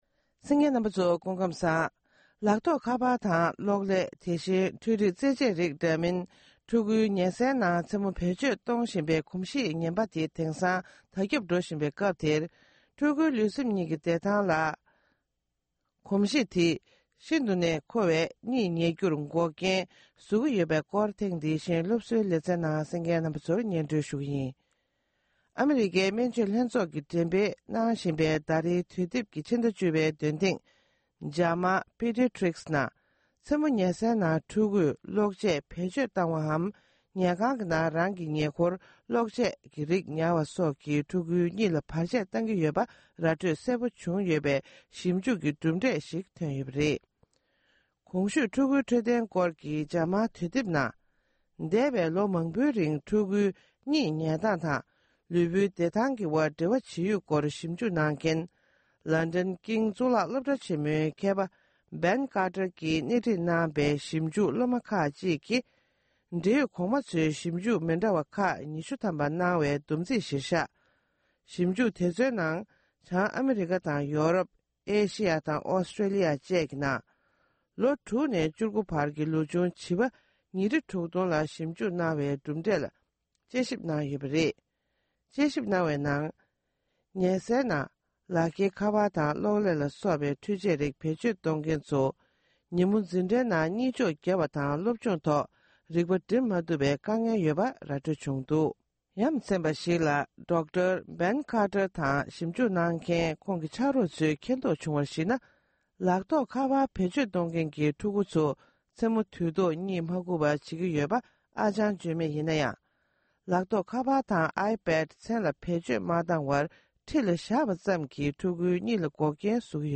འཕྲུལ་ཆས་ཀྱིས་སློབ་སྦྱོང་དང་འཕྲོད་བསྟེན་ལ་ཤུགས་རྐྱེན། སྒྲ་ལྡན་གསར་འགྱུར།